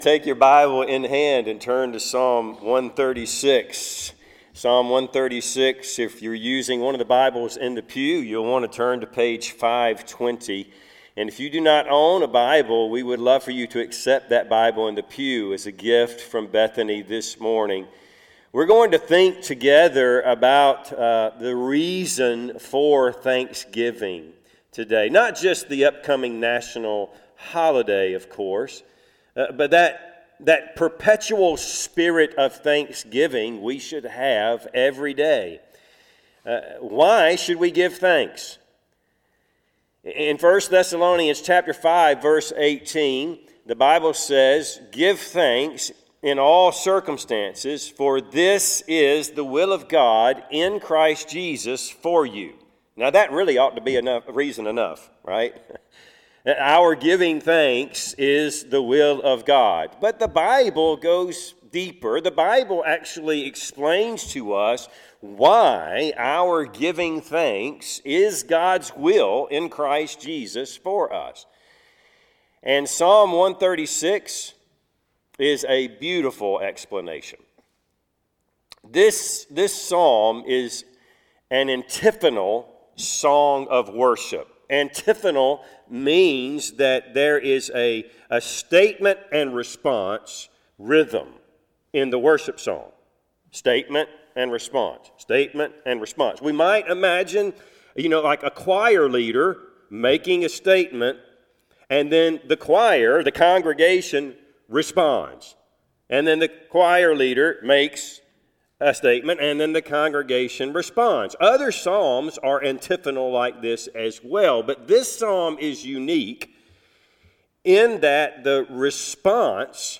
Passage: Psalm 136:1-26 Service Type: Sunday AM